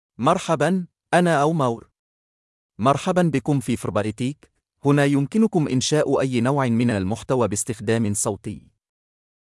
MaleArabic (Libya)
OmarMale Arabic AI voice
Voice sample
Male
Omar delivers clear pronunciation with authentic Libya Arabic intonation, making your content sound professionally produced.